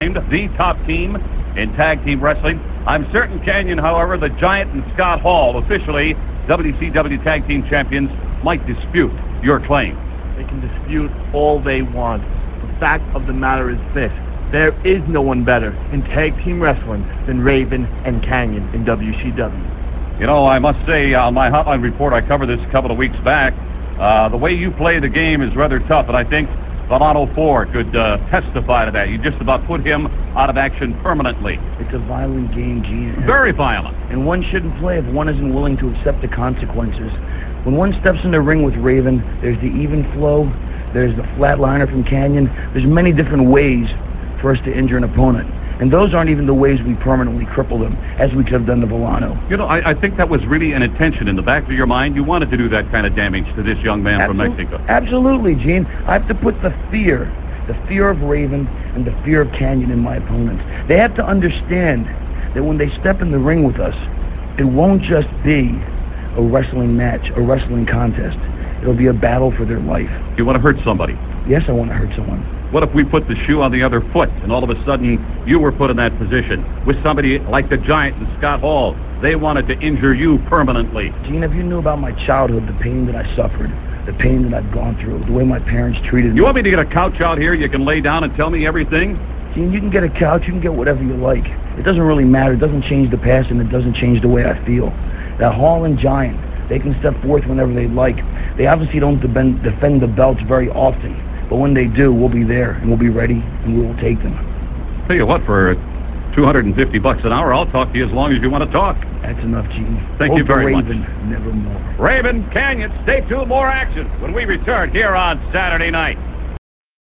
- This interview comes from WCW Saturday Night - [10.10.98]. This interview is with Kanyon and Raven conducted by Mean Gene Okerlund & they talk about injuring Villano IV and their desire to be tag team champions.